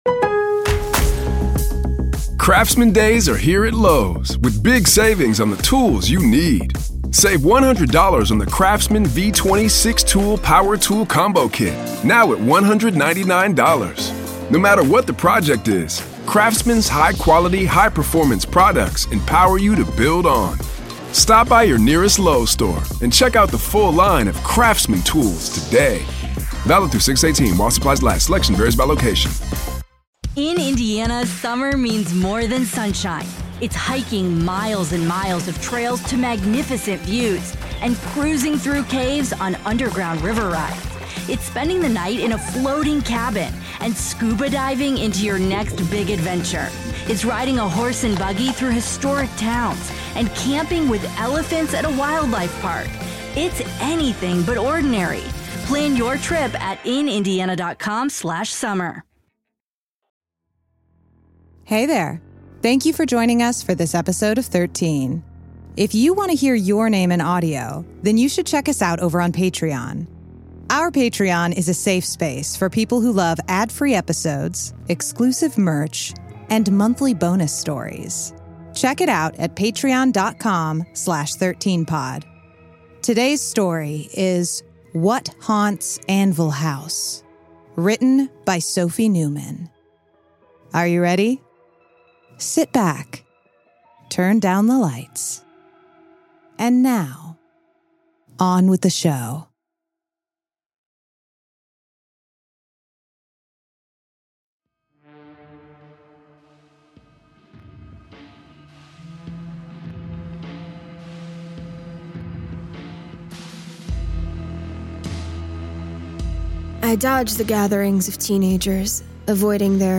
Feature length, atmospheric, spooky stories, explore an eerie universe of supernatural and occult tales.